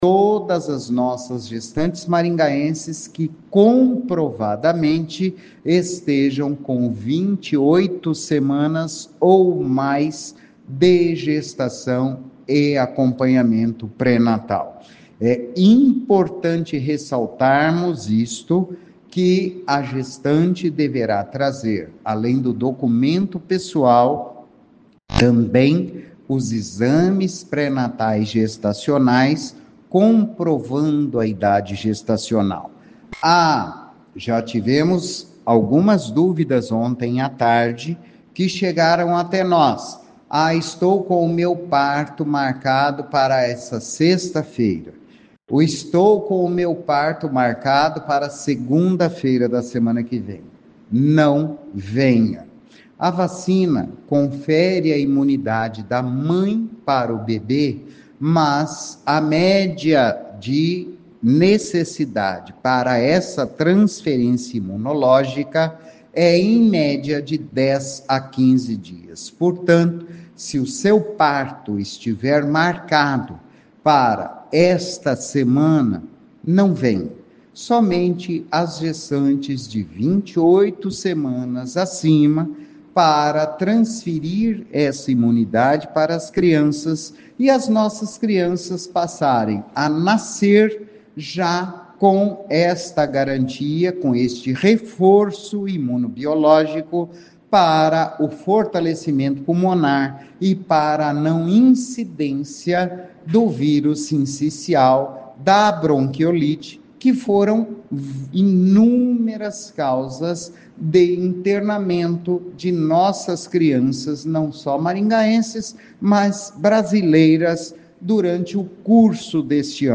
O secretário de Saúde Antônio Carlos Nardi explica quais os critérios para a vacinação.